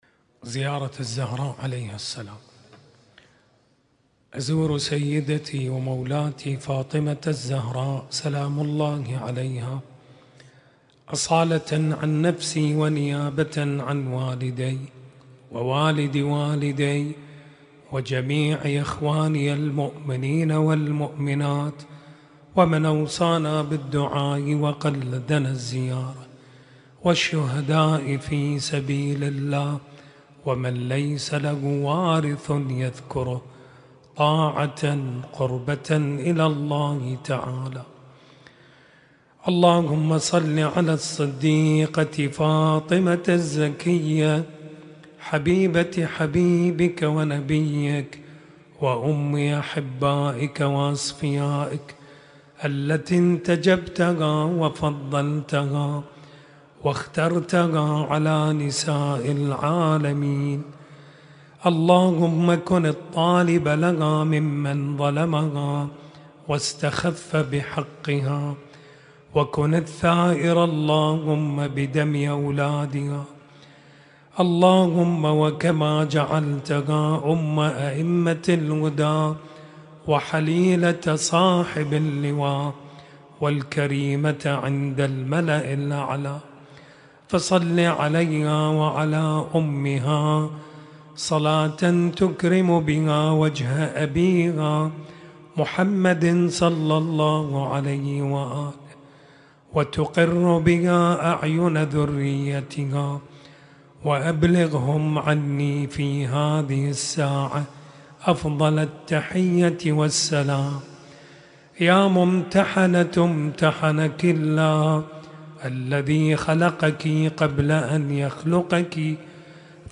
زيارة السيدة فاطمة الزهراء عليها السلام